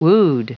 Prononciation du mot wood en anglais (fichier audio)
Prononciation du mot : wood